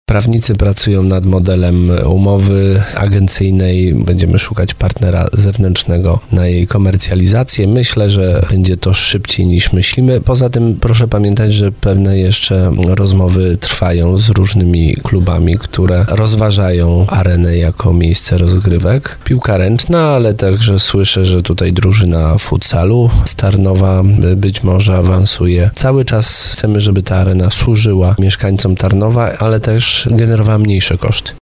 O dalszy los obiektu dopytywali goszczącego w studiu RDN Małopolska prezydenta Tarnowa słuchacze. Jakub Kwaśny w audycji Słowo za Słowo odpowiedział, że trwają procedury, które pozwolą na szukanie partnera w organizacji imprez.